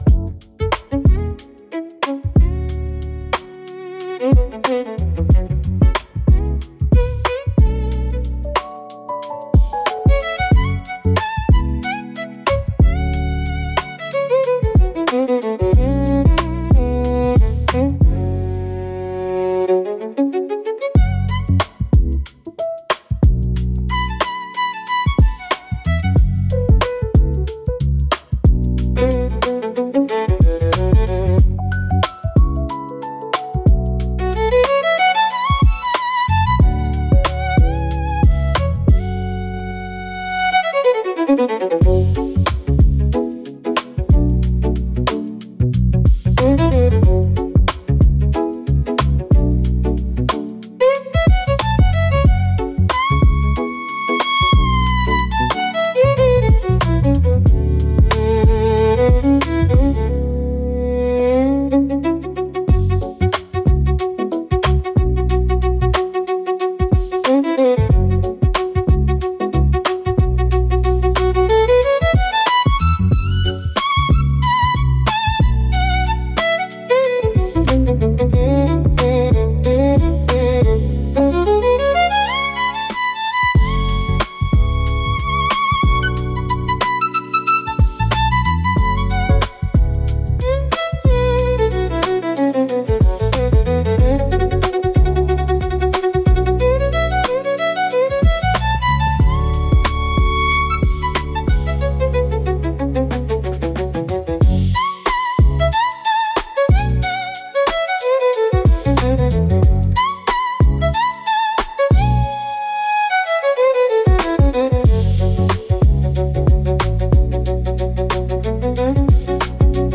Hip-Hop Violin Just Leveled Up 🎻
cinematic violin over hip-hop grooves